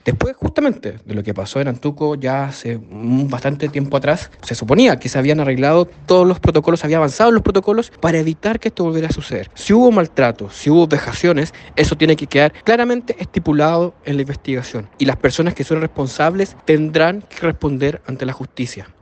El diputado de la UDI y miembro de la Comisión de Defensa, Álvaro Cárter, dijo que en Chile no puede volver a ocurrir algo similar a la tragedia de Antuco, agregando que si hubo maltratos y vejaciones, tiene que quedar claramente estipulado en la investigación.